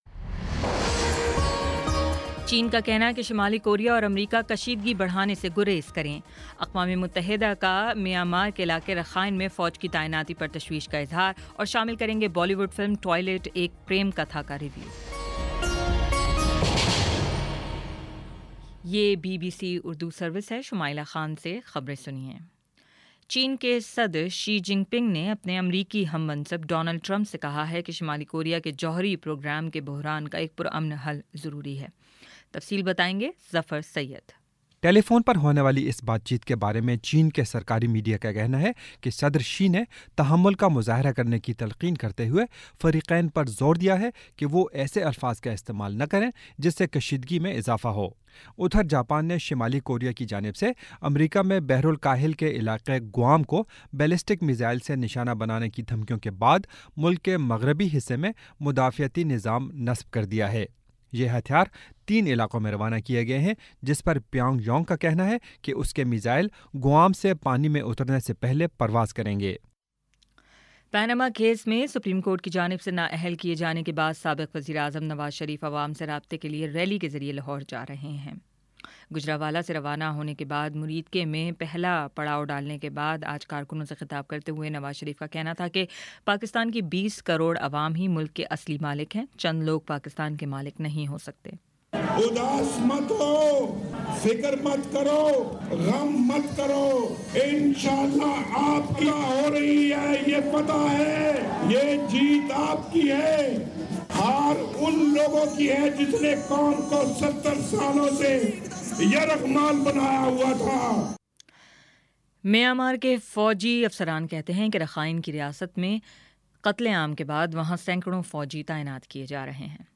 اگست12 : شام سات بجے کا نیوز بُلیٹن